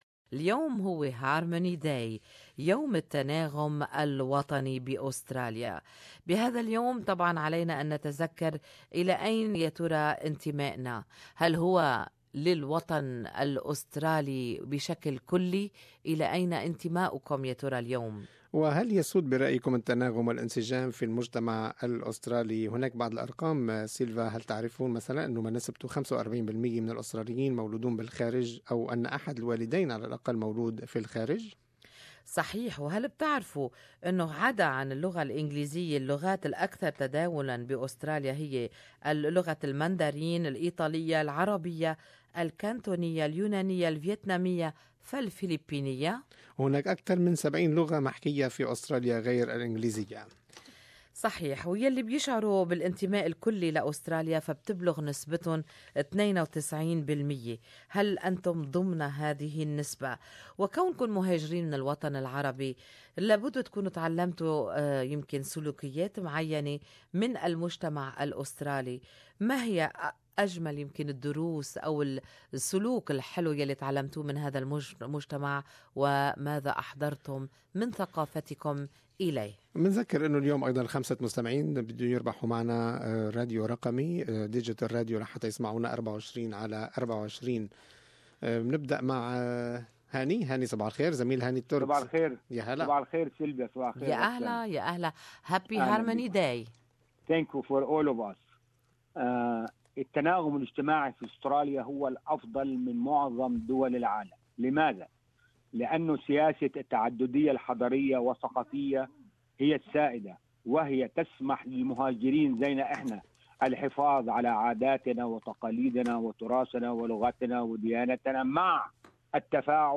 Talkback listeners opinons .